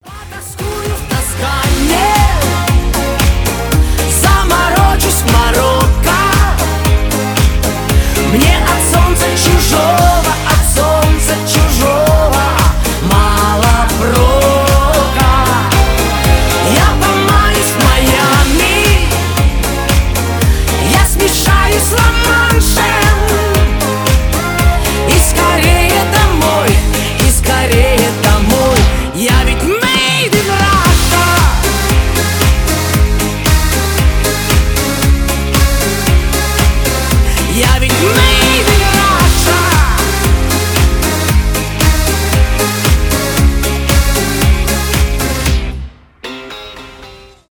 поп , веселые